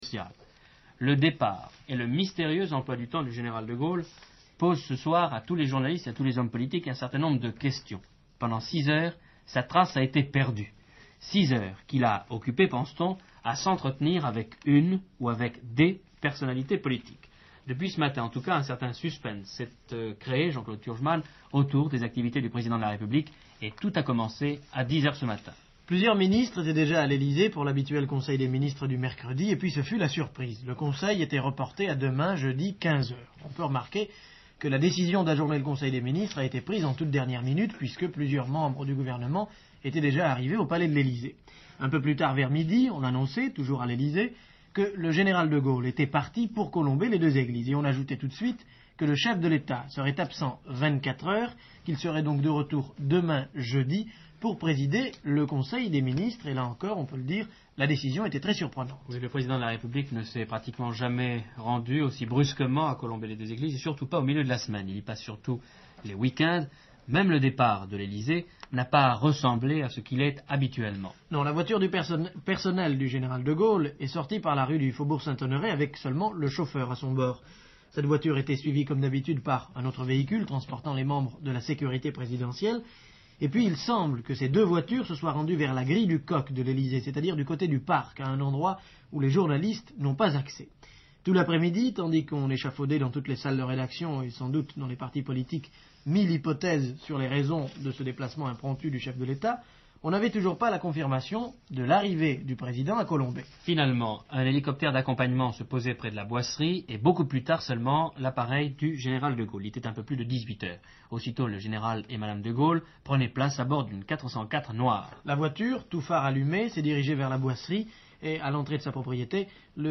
journal de 20 heures de France Inter ce jour-là